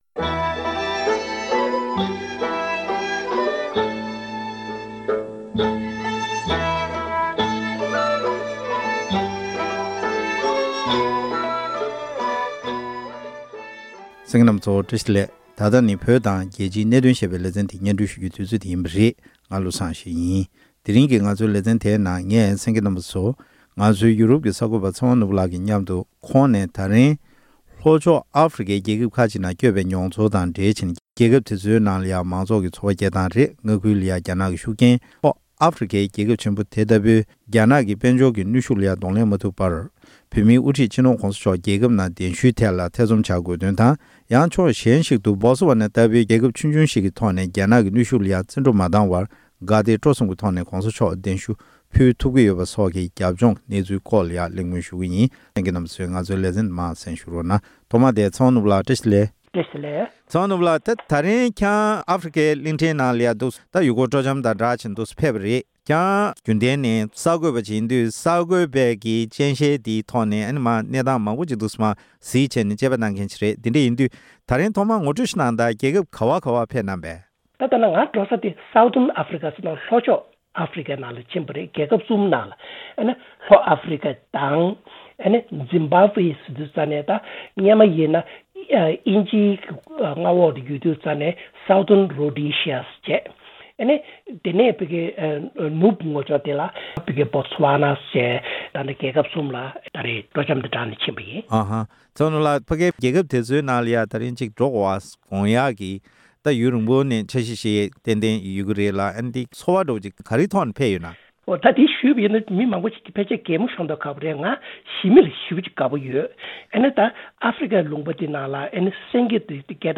ལྷན་གླེང་མོལ་ཞུས་པར་གསན་རོགས